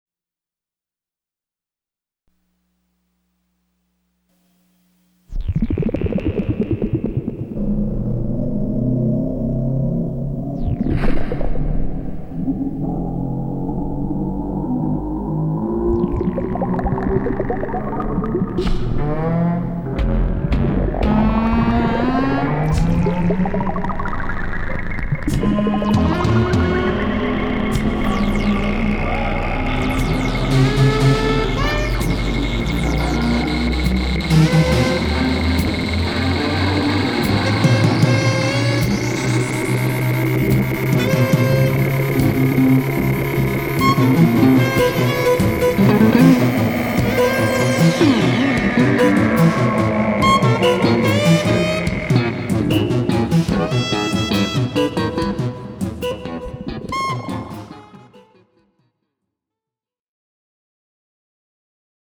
ImprovyZed Music for Movies.
bass
sax, clarinet and flutes
drums, percussion, and keyboards